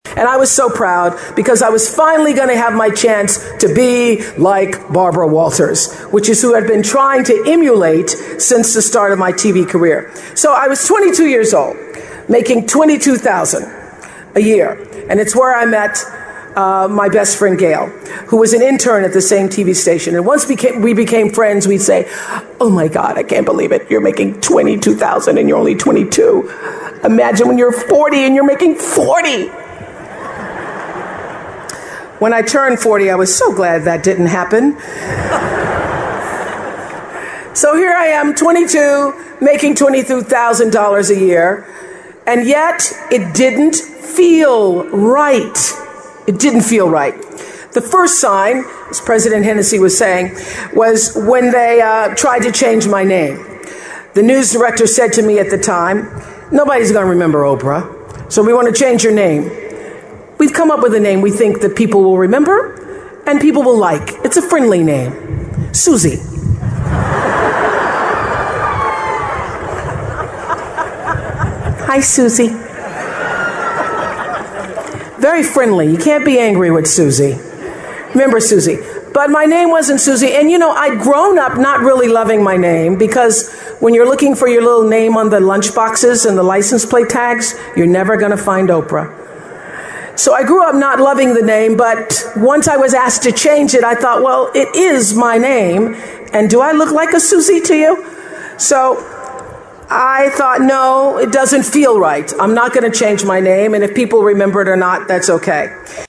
在线英语听力室名人励志英语演讲 第144期:感觉失败及寻找幸福(6)的听力文件下载,《名人励志英语演讲》收录了19篇英语演讲，演讲者来自政治、经济、文化等各个领域，分别为国家领袖、政治人物、商界精英、作家记者和娱乐名人，内容附带音频和中英双语字幕。